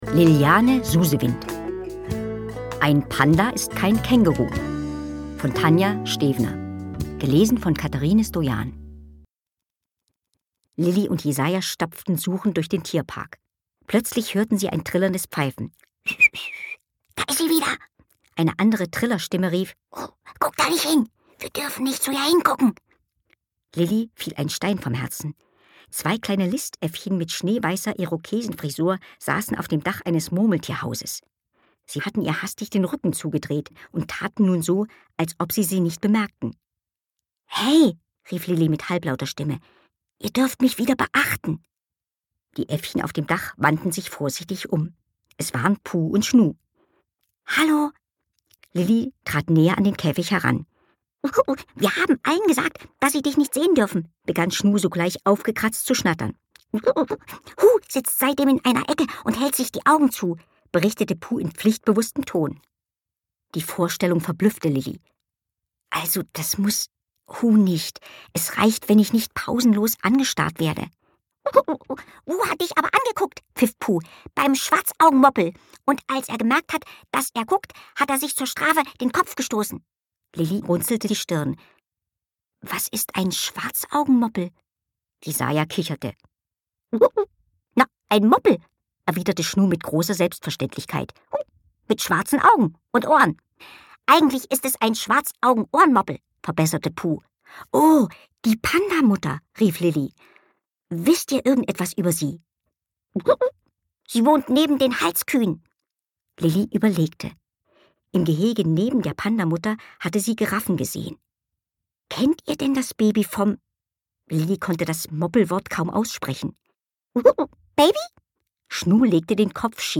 Facettenreich erschafft sie die schönsten Hörerlebnisse und gibt allen Tieren eine hinreißende Stimme.
Sie ist ein wahres Stimmwunder und verleiht allen Tieren ganz eigene Persönlichkeiten.